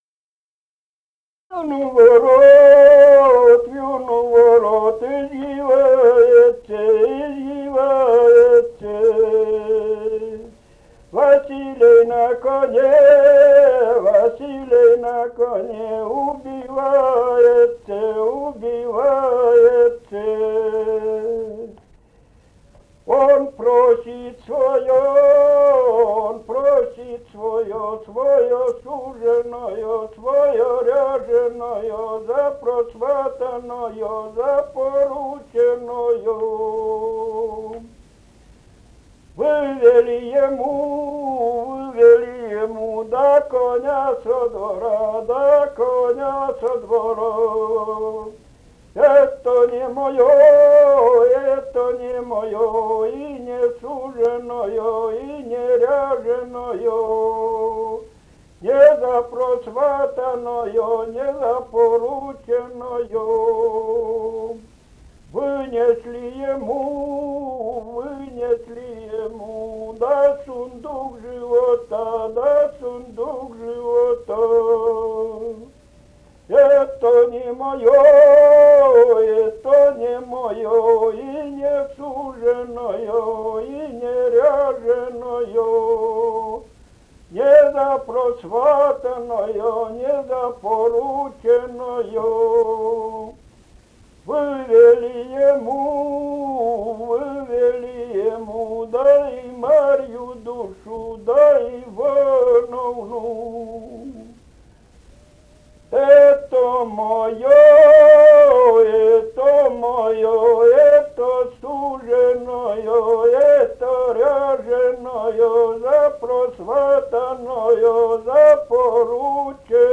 Живые голоса прошлого 043. «Вью у ворот извивается» (свадебная).